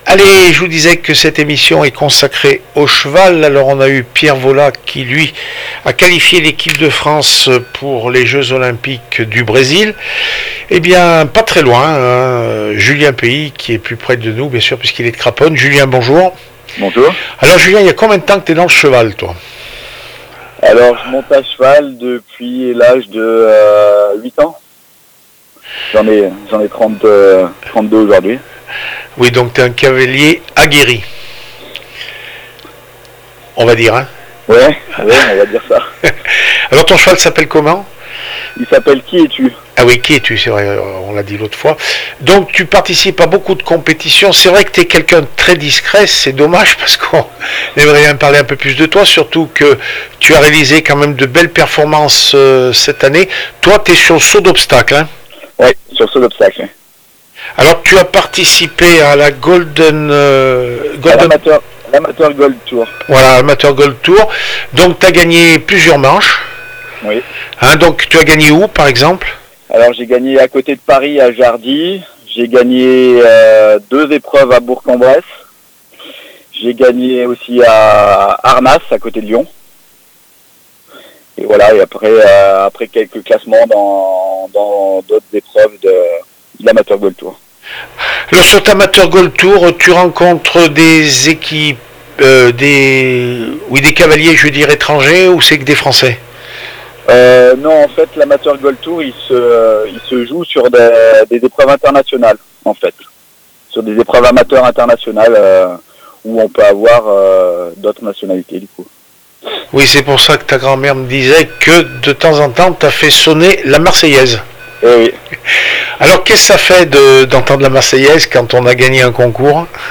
5 novembre 2015   1 - Sport, 1 - Vos interviews   No comments